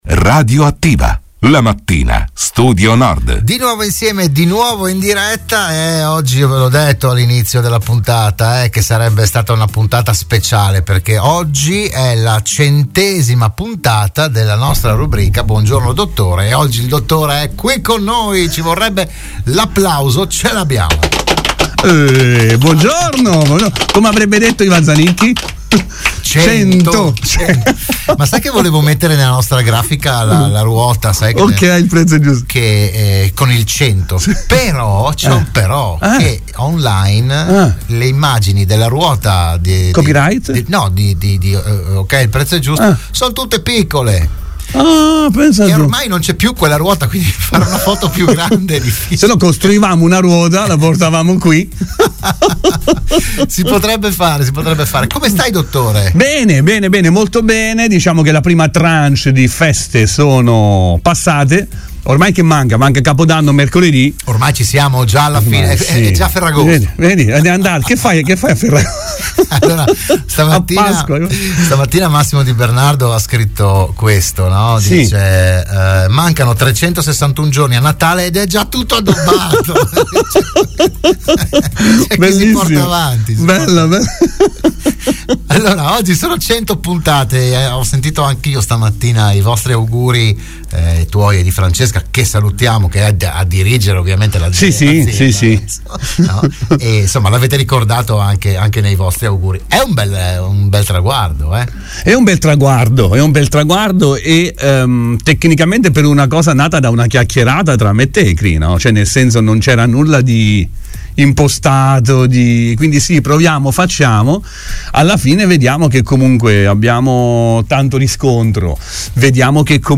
Oggi è andata in onda la centesima puntata per “Buongiorno Dottore”, il programma di prevenzione e medicina in onda all’interno della trasmissione di Radio Studio Nord “RadioAttiva”.